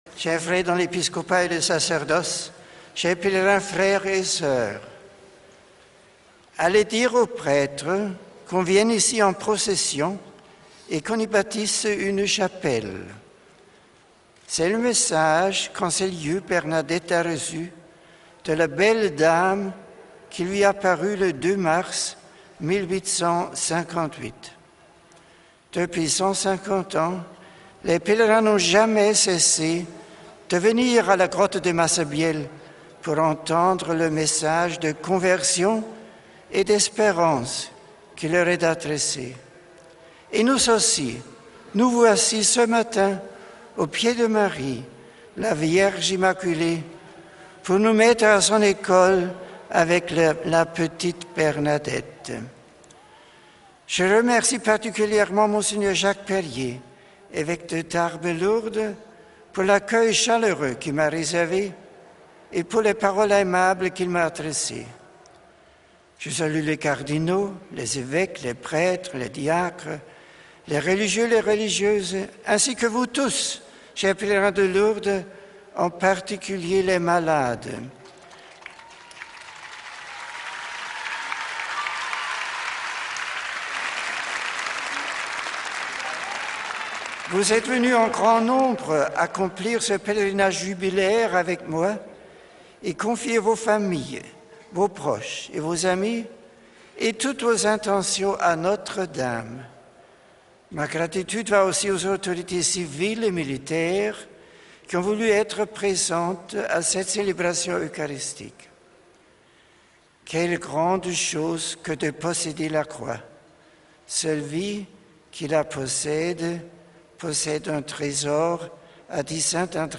Messe in Lourdes - Die Predigt im Volltext